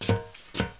metalshuffle1.wav